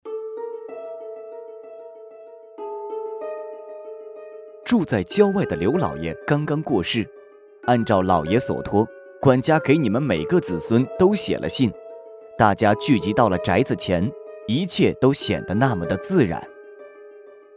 角色-男10-旁白.mp3